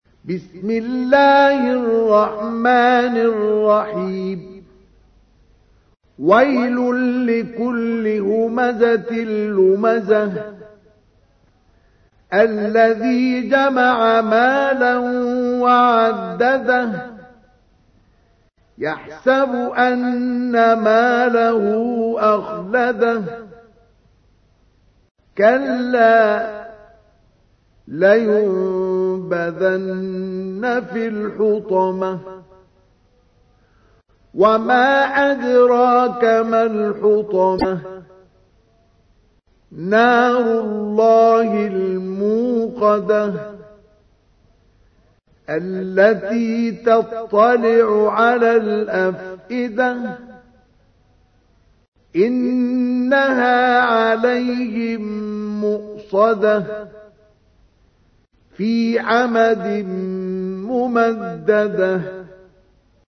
تحميل : 104. سورة الهمزة / القارئ مصطفى اسماعيل / القرآن الكريم / موقع يا حسين